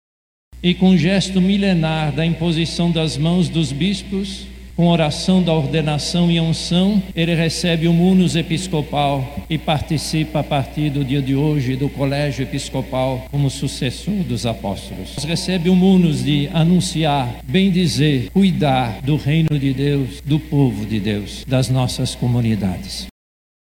Durante a ordenação presidida pelo arcebispo de Manaus, cardeal Leonardo Steiner; que teve como bispos coordenantes o bispo de Roraima, Dom Evaristo Pascoal Spengler, e o bispo emérito da Barra (BA), Dom Luiz Flavio Cappio; o compromisso em servir a Igreja e aos propósitos do Senhor foram enfatizados pelo celebrante.
SONORA-1-CARDEAL.mp3